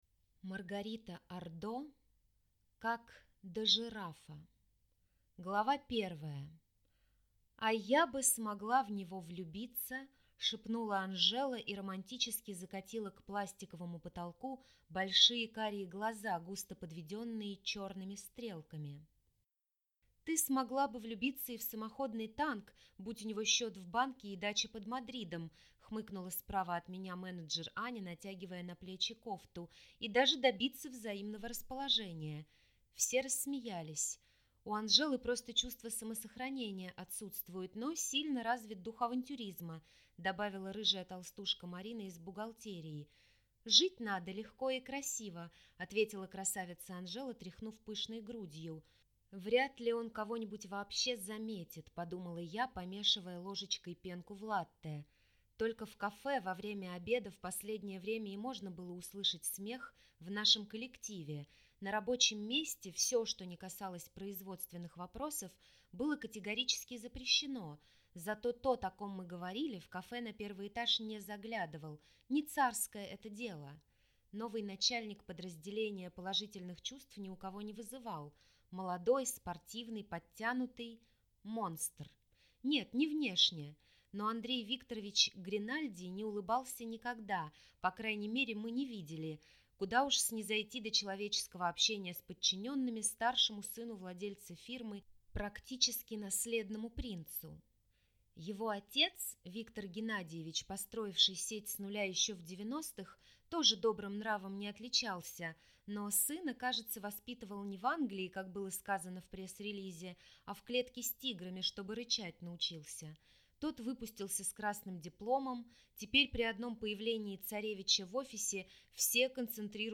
Аудиокнига Как до Жирафа…